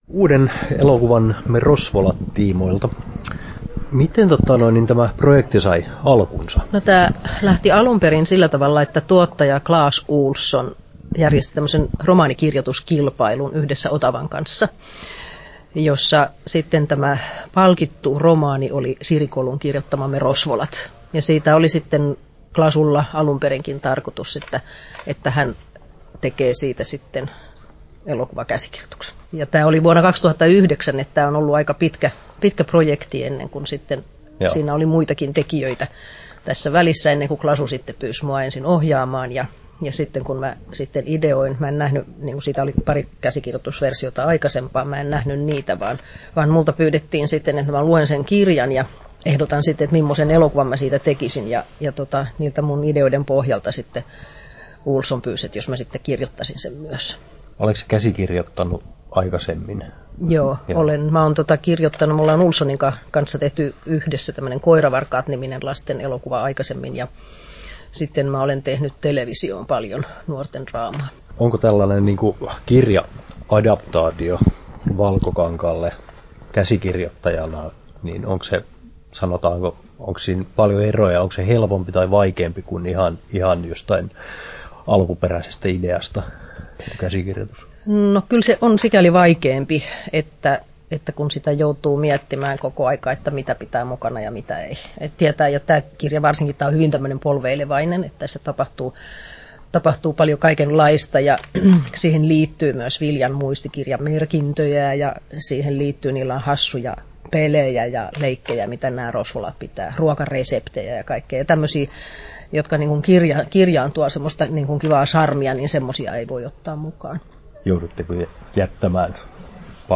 Haastattelussa